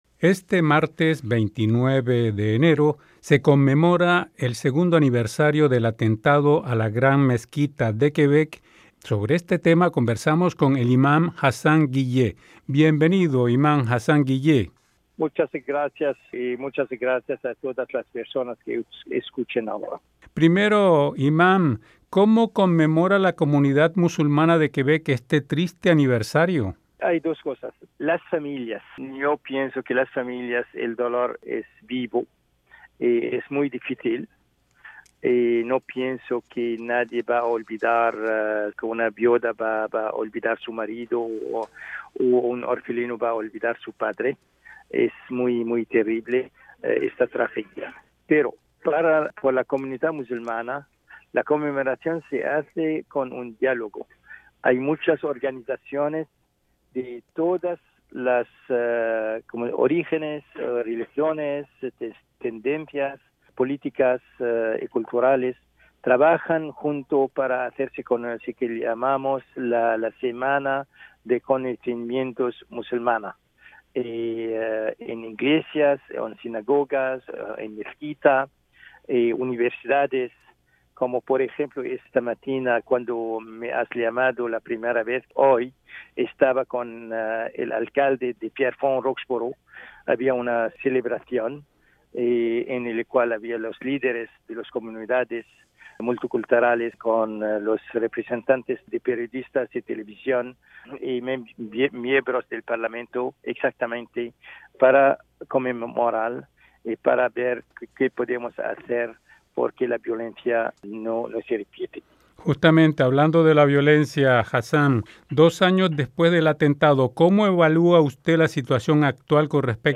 Sobre este triste episodio de la provincia de Quebec conversamos con el